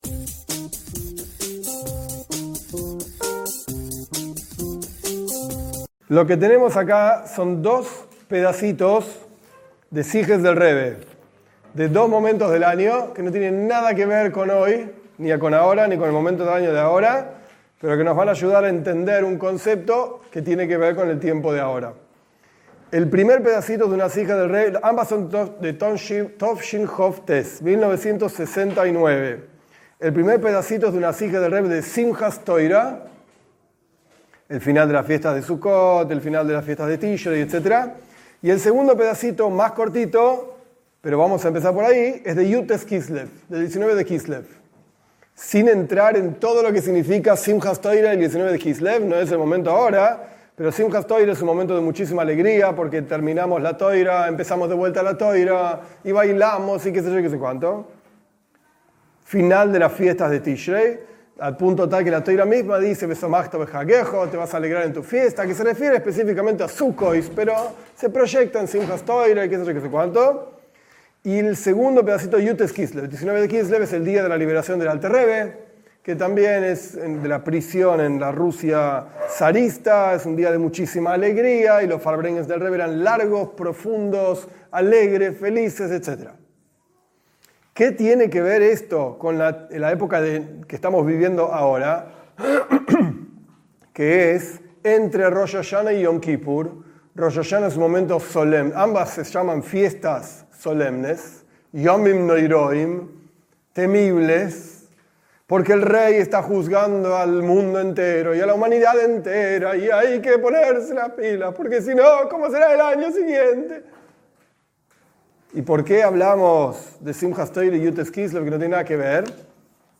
Esta clase está basada en dos partes de charlas del Rebe sobre la Mitzvá de Teshuvá - arrepentimiento. El Rebe explica que todas las Mitzvot hay que cumplirlas con alegría.